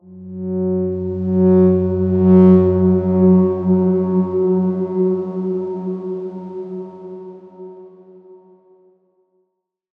X_Darkswarm-F#2-pp.wav